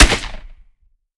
12ga Pump Shotgun - Gunshot A 001.wav